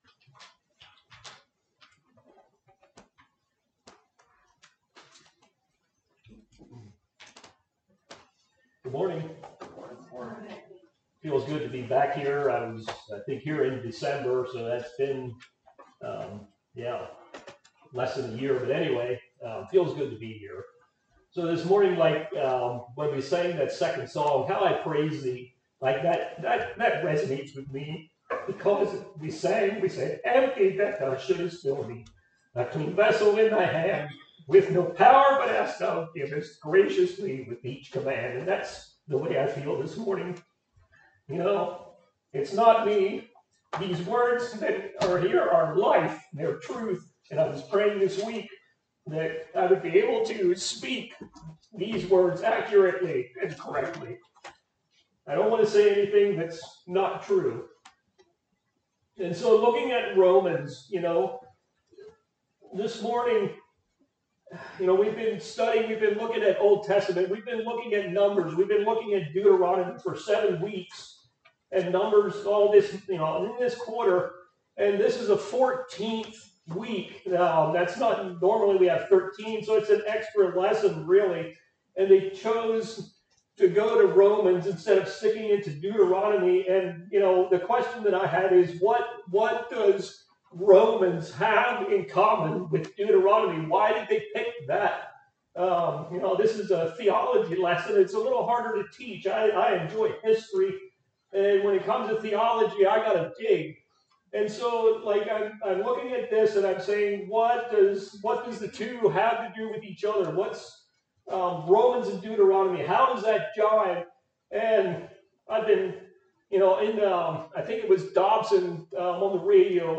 Sunday School - Word of Life Mennonite Fellowship